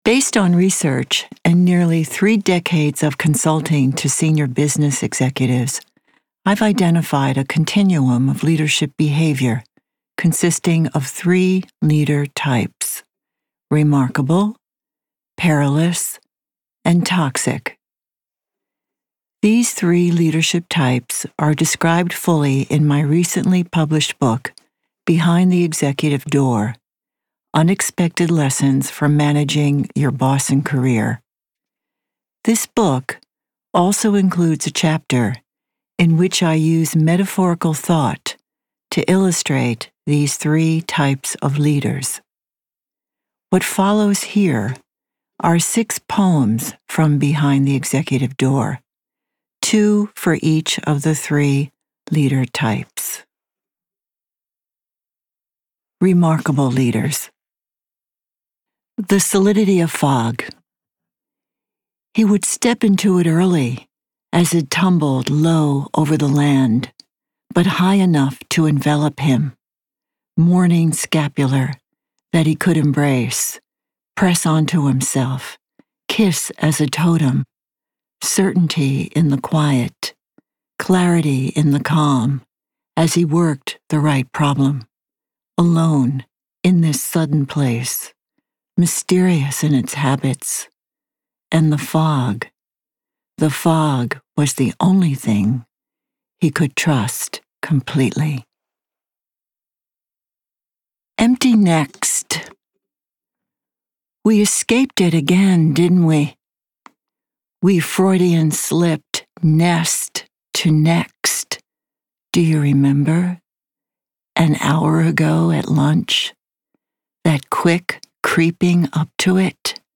Selected readings from Standing on Marbles: Three Leader Types in Verse and Imagery